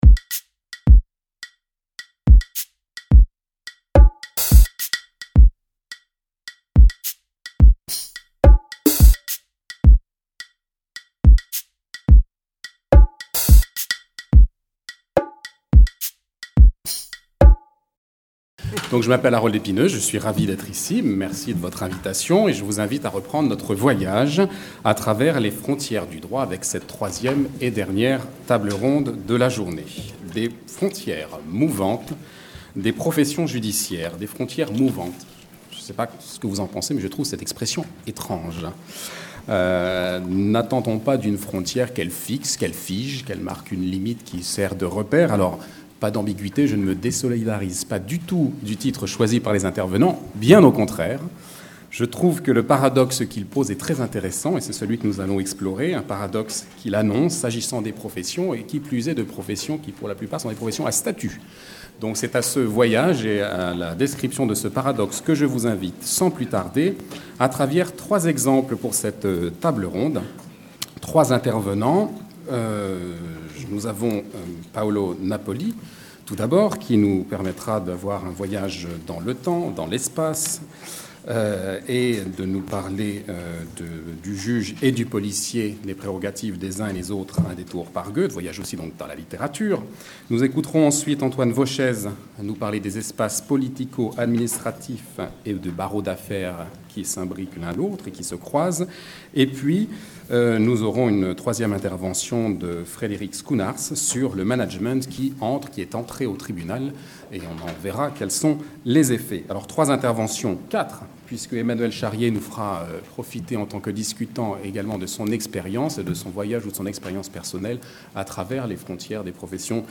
Table ronde Les frontières mouvantes des professions judiciaires